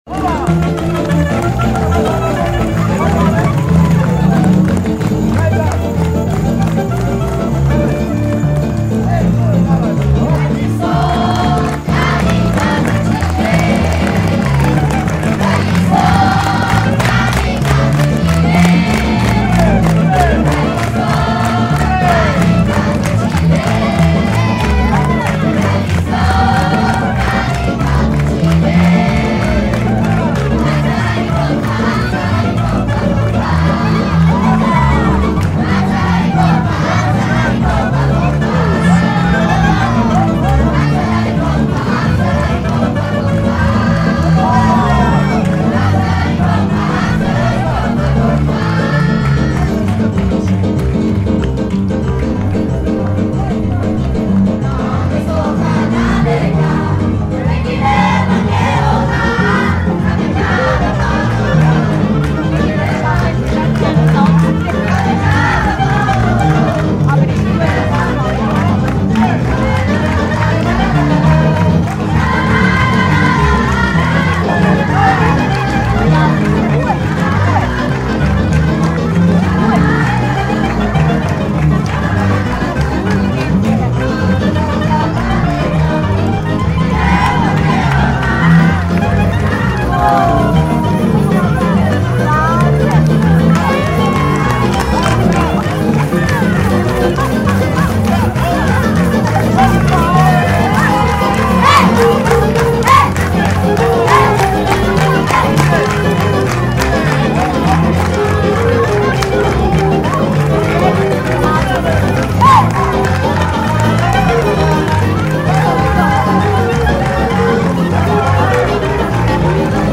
Les enfants de la région de Gstaad chantent avec les Roms
Concert à Gstaad